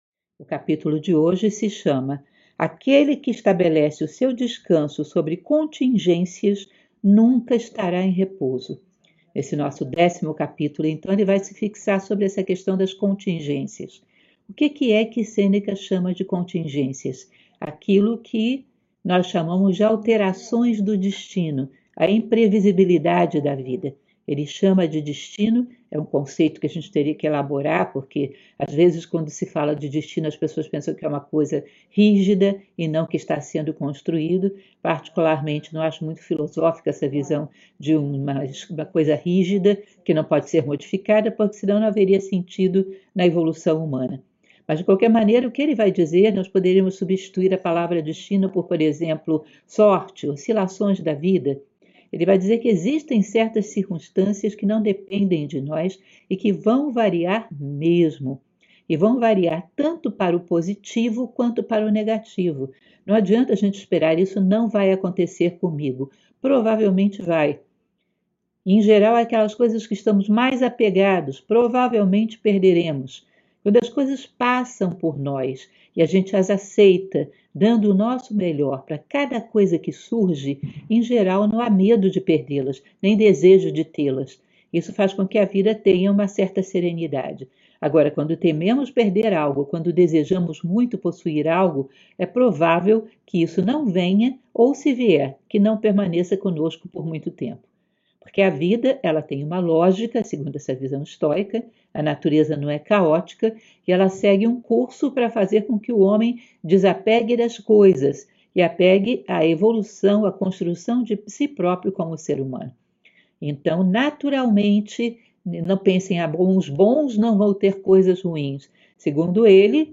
# 619 J - Live semanal de leitura comentada do livro "A vida feliz, de Sêneca"